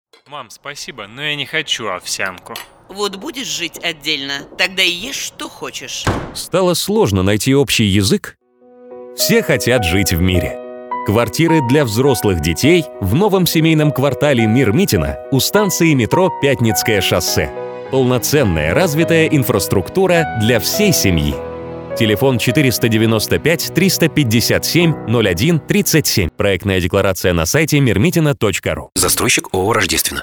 Аудиоролик, формат mp3, хронометраж – до 30 сек